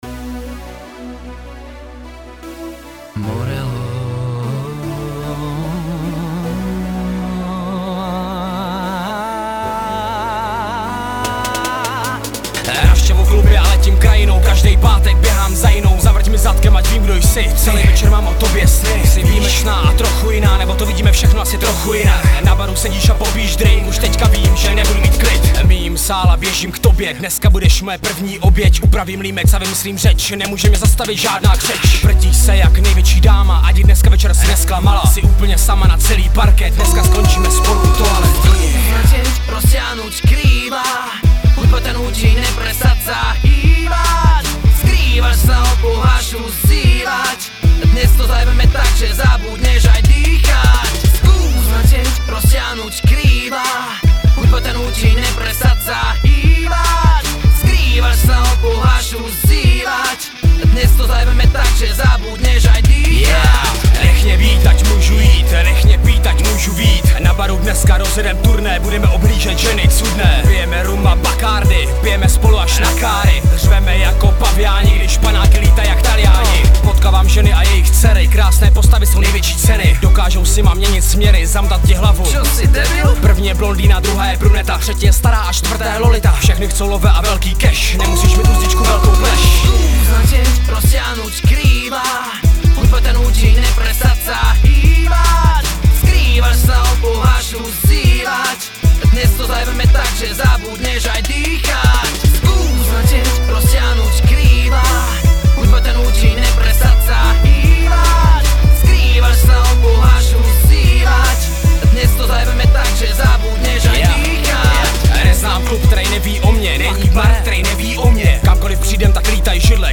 Styl: Hip-Hop Rok